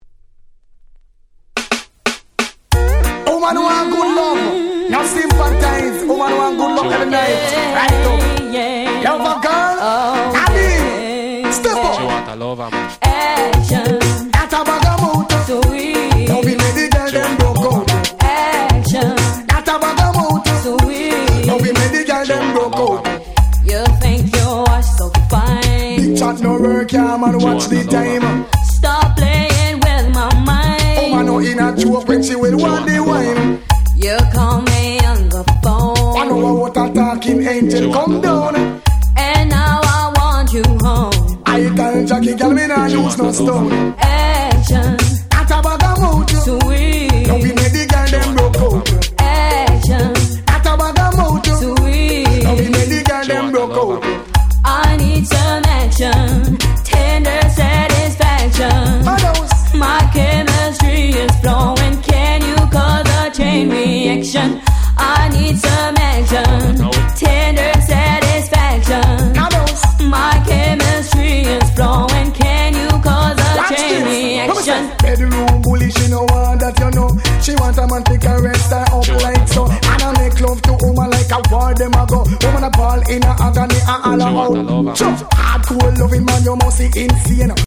94' Super Dancehall Reggae Classic !!
女性Vocalも絡むキャッチーな1曲！